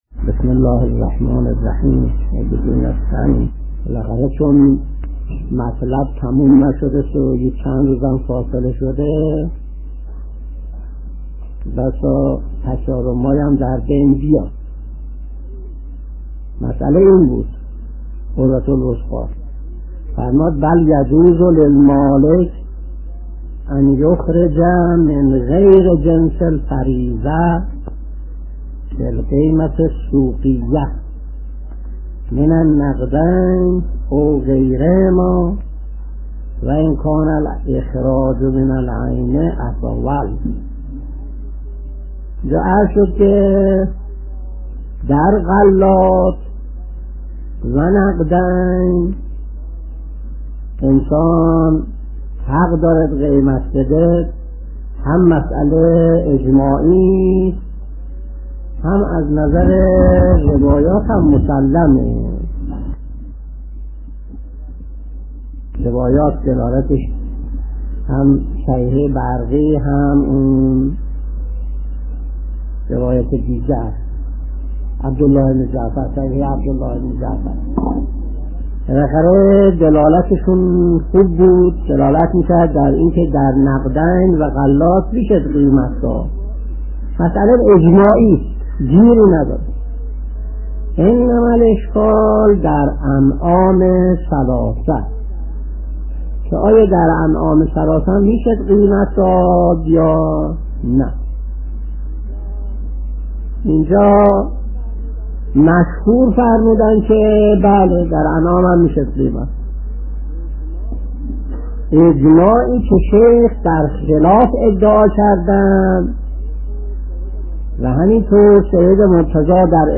درس 111 : (13/10/1361)